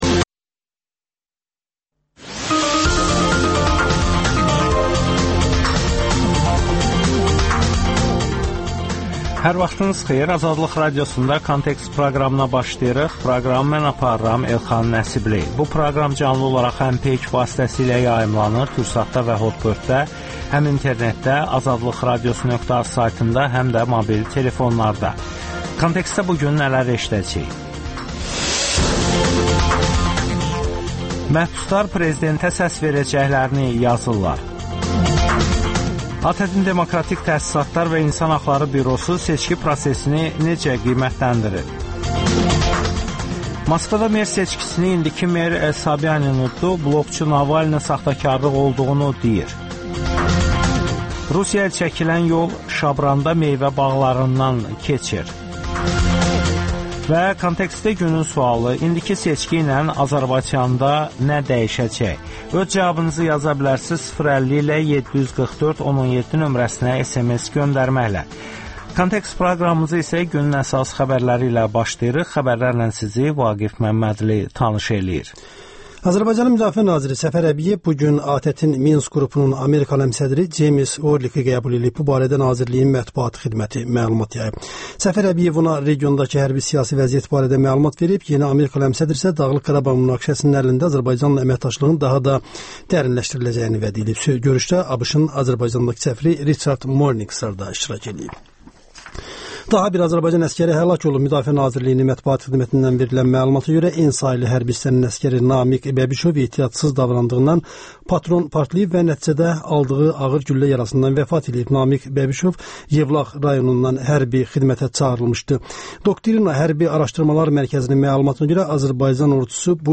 Kontekst - ATƏT-in baş müşahidəçisi ilə müsahibə